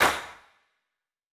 TC3Clap1.wav